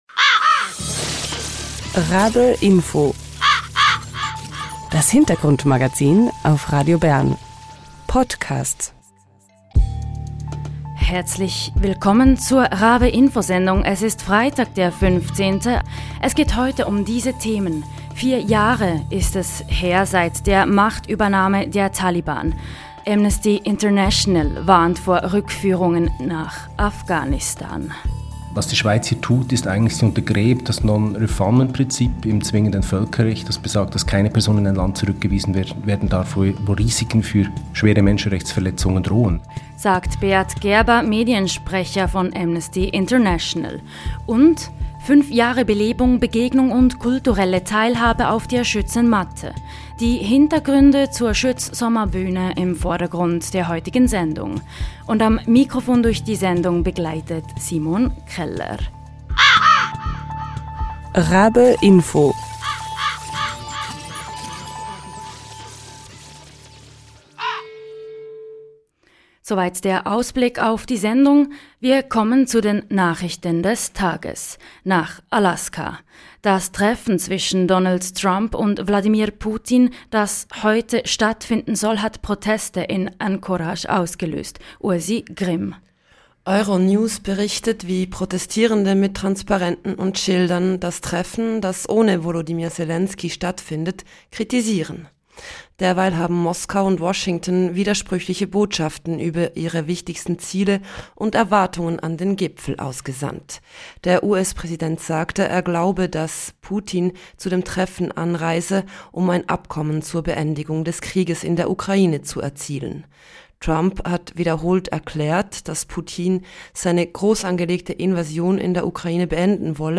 Live-Talk